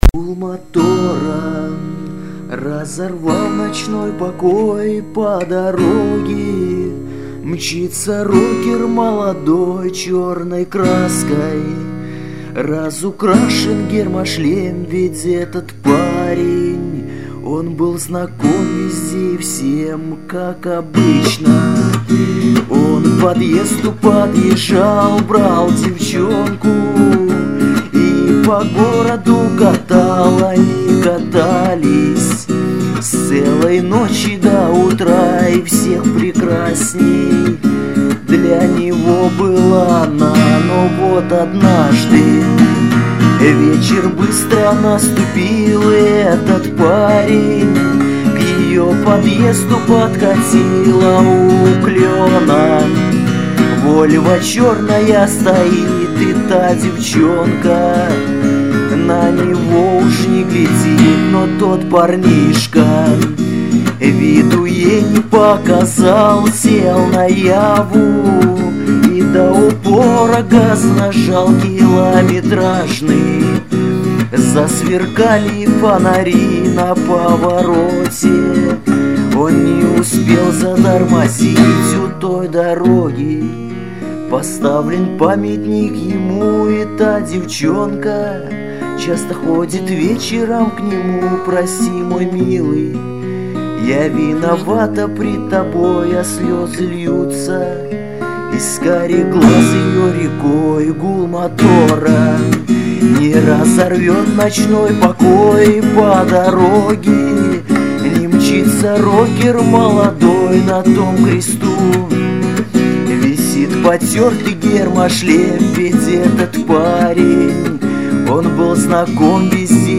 Главная » Файлы » Песни под гитару » Песни у костра